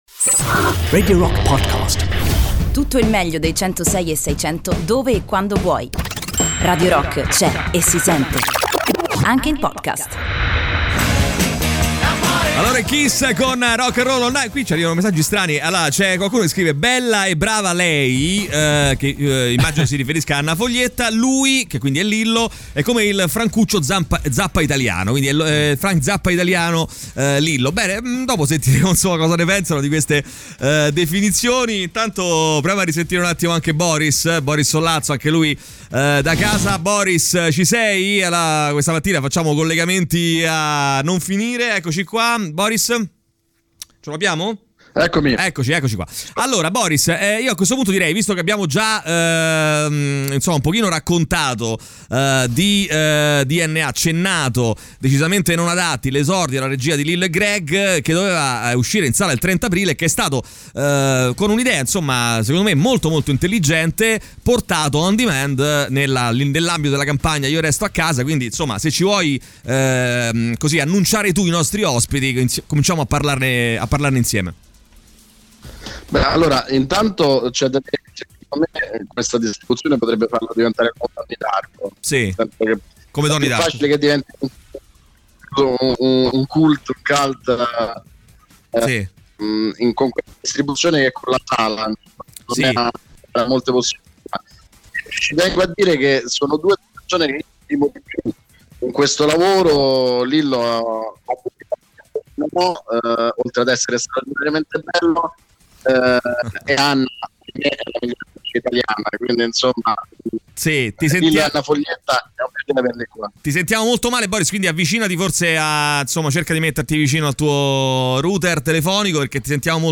in collegamento telefonico